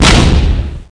explo4.mp3